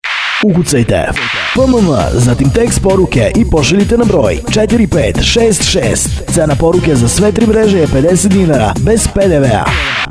SMS radio džingl
SMS radio dzingl Glasanje